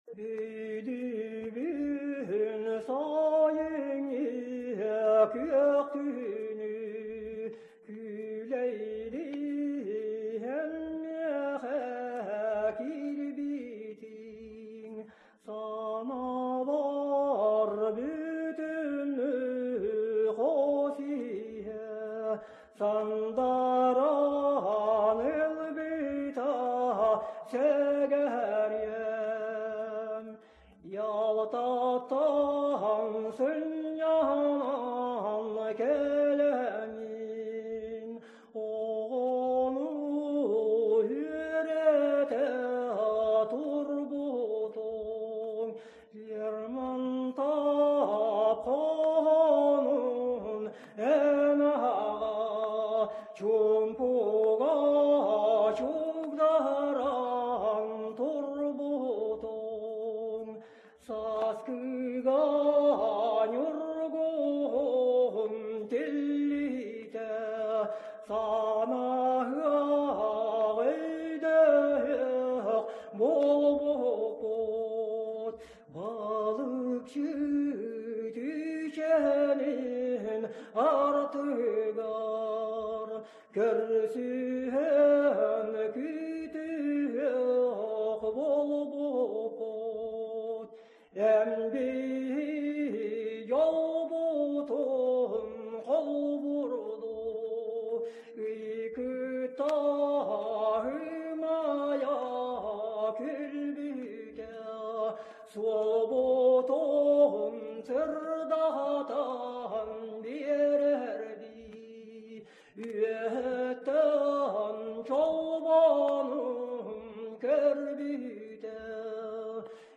Якутский фольклор